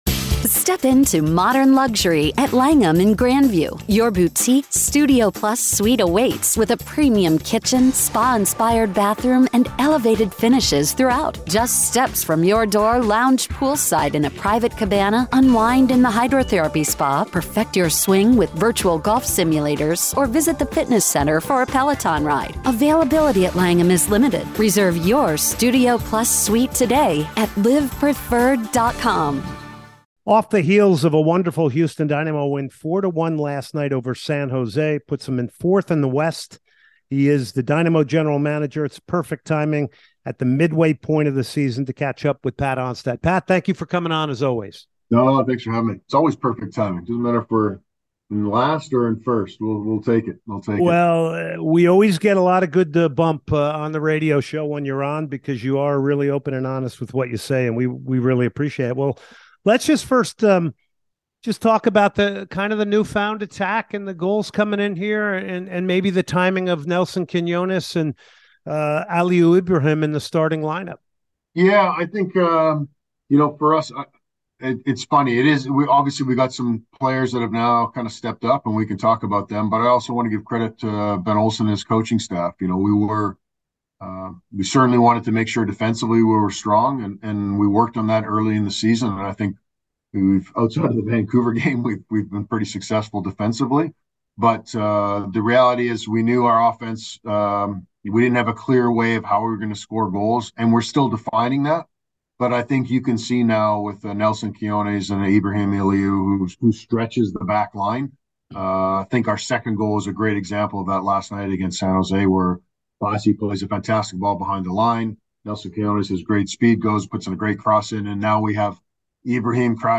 joins the show for an interview.